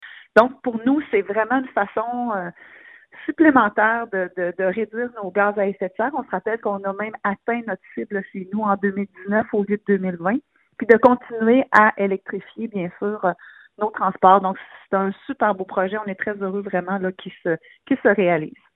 Pour la mairesse, Geneviève Dubois, le projet est une suite logique au projet d’autopartage SAUVéR, déjà en place à Nicolet :